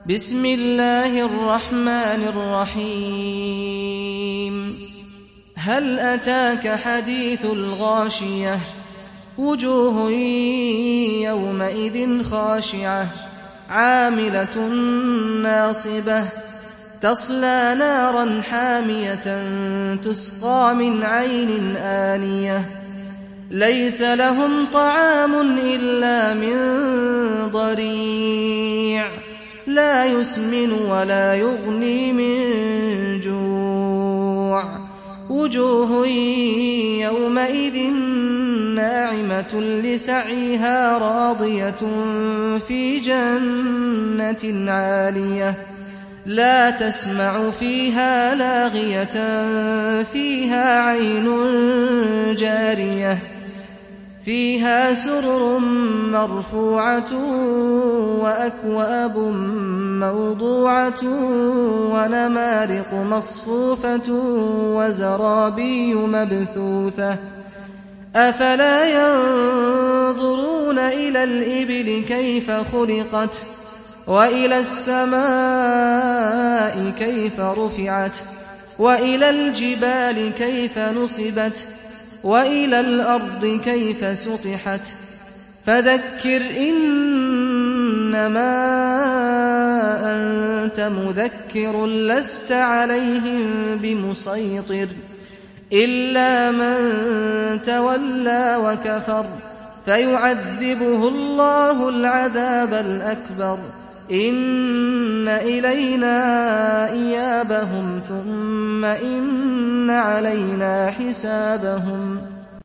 Gaşiye Suresi İndir mp3 Saad Al-Ghamdi Riwayat Hafs an Asim, Kurani indirin ve mp3 tam doğrudan bağlantılar dinle